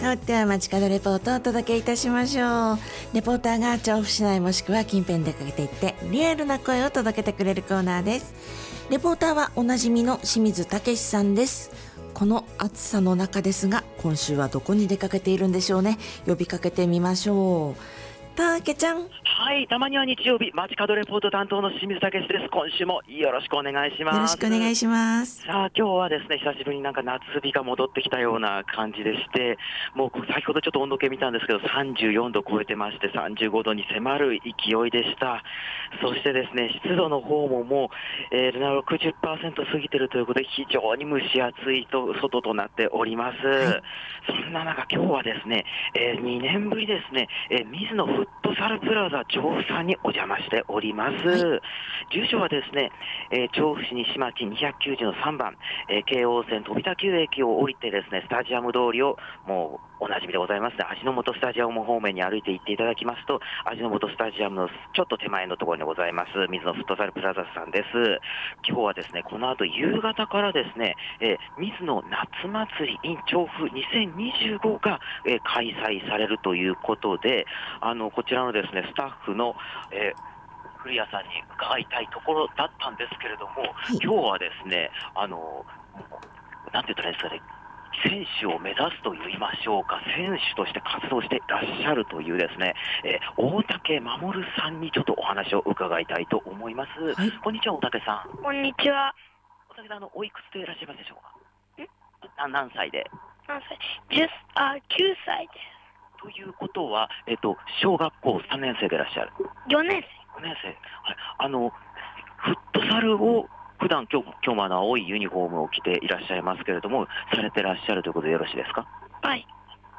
久々の酷暑の下からお届けした街角レポートは、飛田給にある 「ミズノフットサルプラザ調布」さんからのレポートでした！